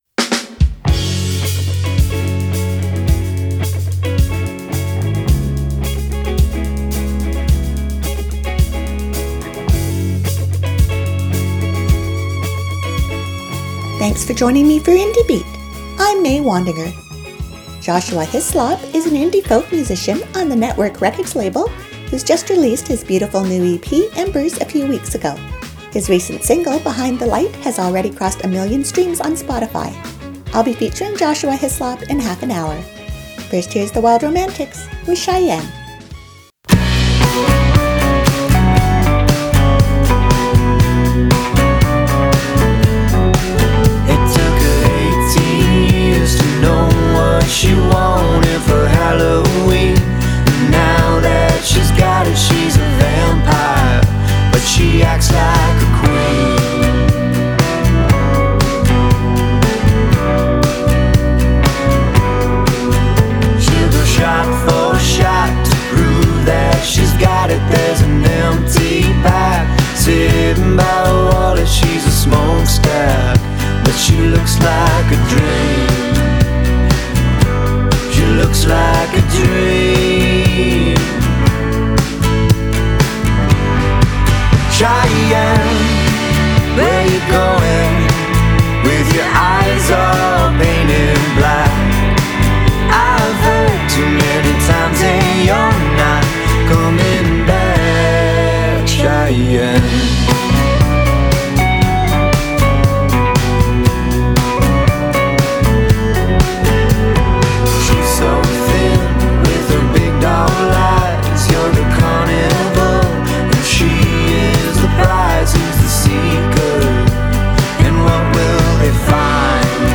Western Canadian indie music mix